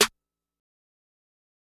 Metro Snr-2.wav